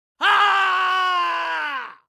Agony Yelp
Agony Yelp is a free horror sound effect available for download in MP3 format.
yt_NO4U5ztG1zg_agony_yelp.mp3